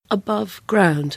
/əˈbʌv/